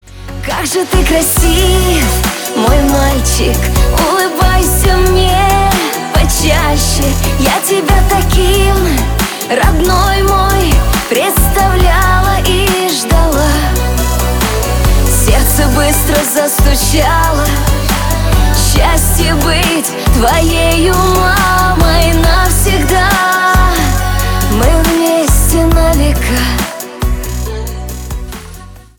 • Качество: 320, Stereo
женский голос
спокойные
нежные